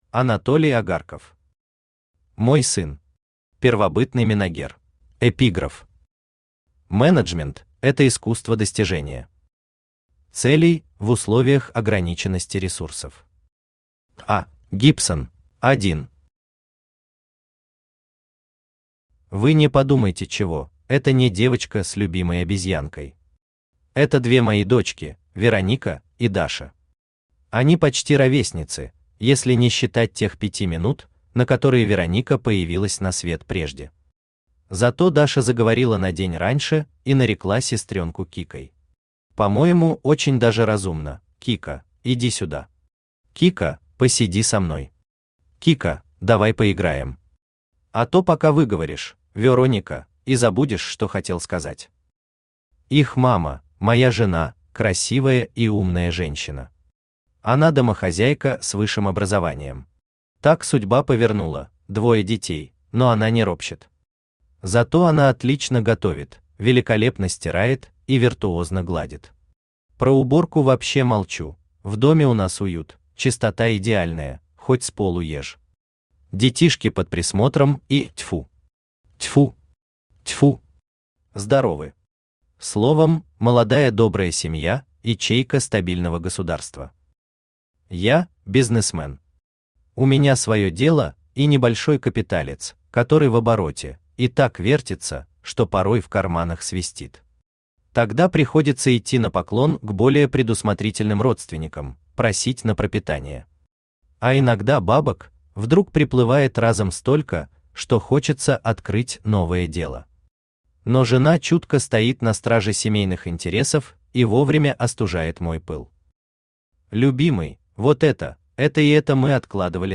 Аудиокнига Мой сын. Первобытный менагер | Библиотека аудиокниг
Первобытный менагер Автор Анатолий Агарков Читает аудиокнигу Авточтец ЛитРес.